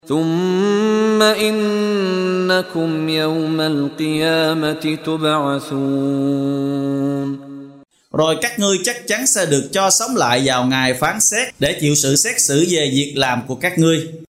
Đọc ý nghĩa nội dung chương Al-Muminun bằng tiếng Việt có đính kèm giọng xướng đọc Qur’an